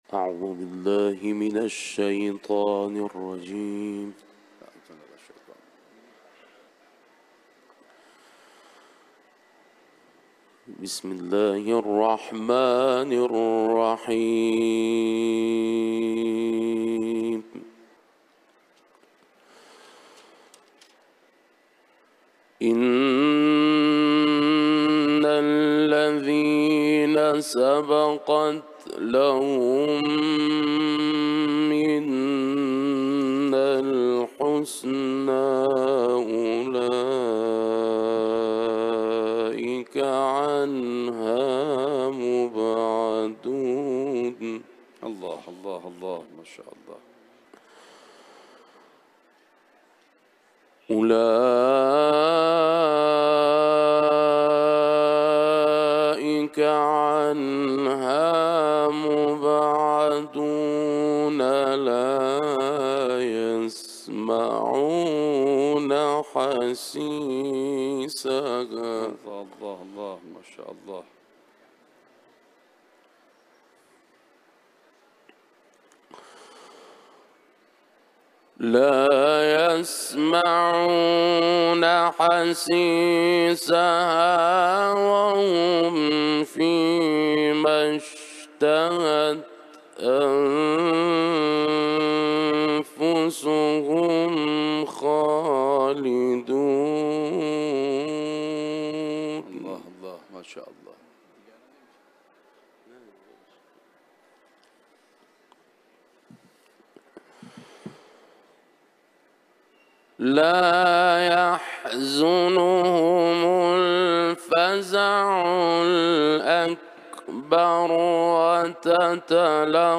Enbiyâ ve Fatiha suresinden ayetler tilavet etti.